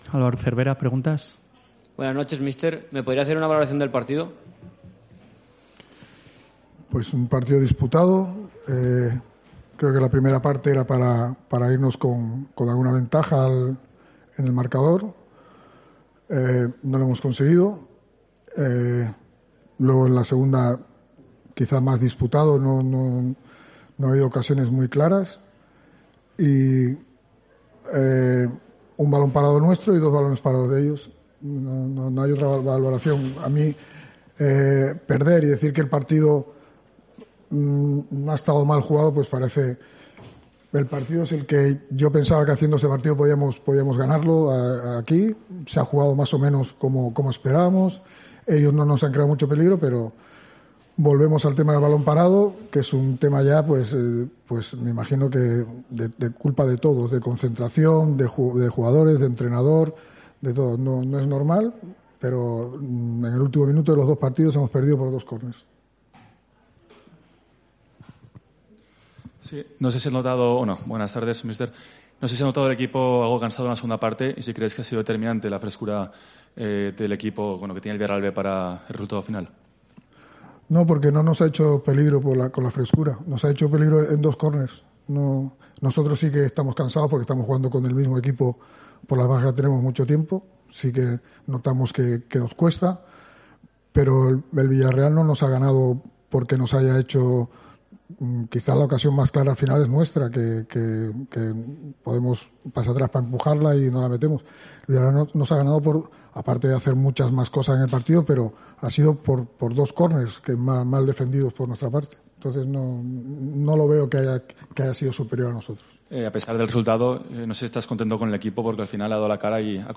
Rueda de prensa Cervera (post Villarreal B)